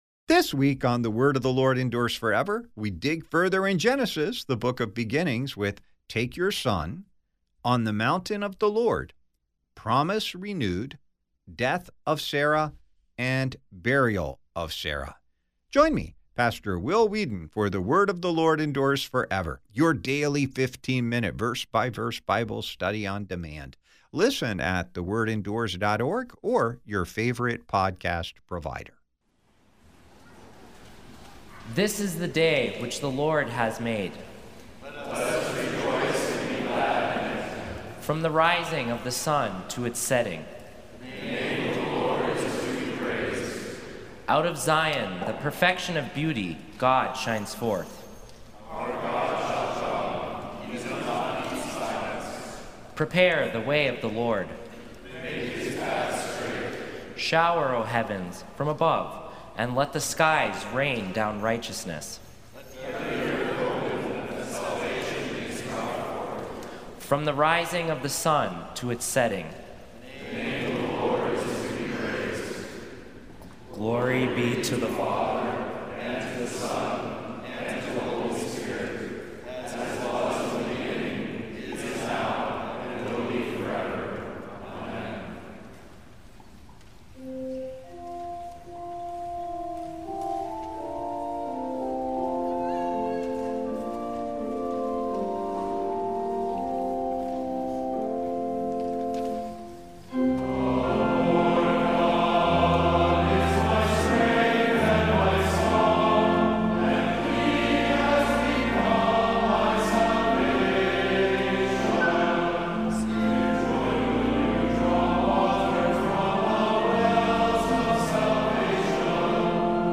On the campus of Concordia Theological Seminary, Fort Wayne, Indiana.